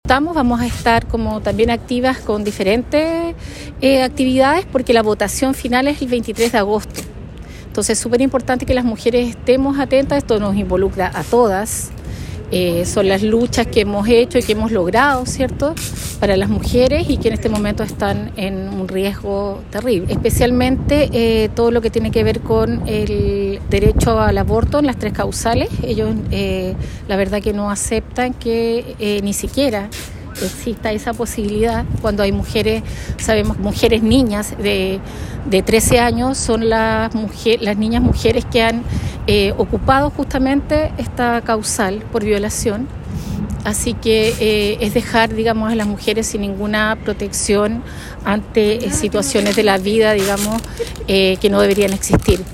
actualidad Entrevista Local